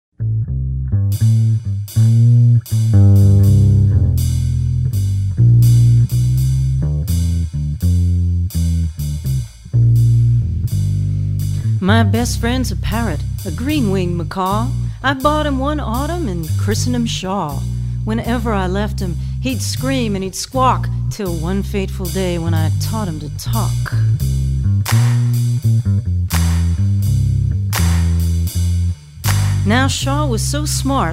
Vocal and
▪ The full vocal track.